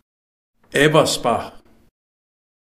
německá výslovnost, zvuk v hornolužickém nářečí Aberschbuch, hornolužickosrbsky Habrachćicy[2]) je bývalé město, místní část města Ebersbach-NeugersdorfHorní Lužiciněmecké spolkové zemi Sasko.
Ebersbach_de.ogg